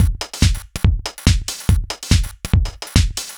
Index of /musicradar/uk-garage-samples/142bpm Lines n Loops/Beats
GA_BeatA142-02.wav